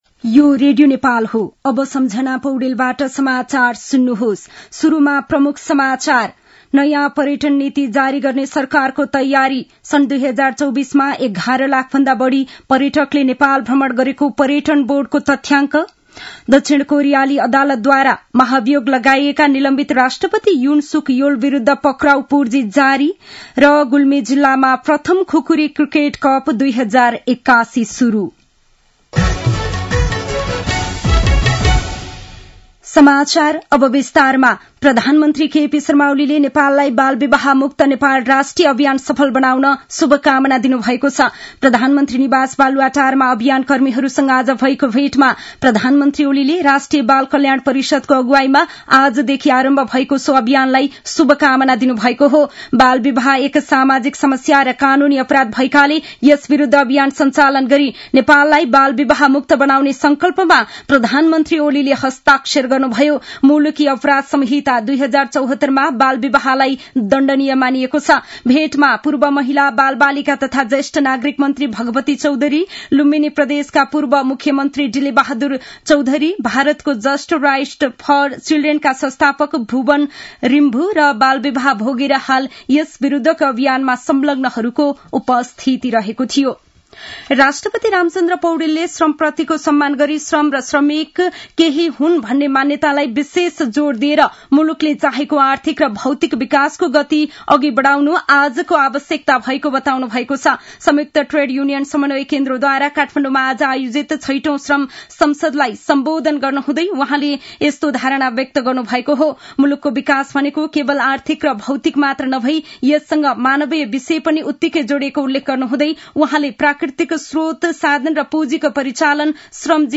दिउँसो ३ बजेको नेपाली समाचार : १७ पुष , २०८१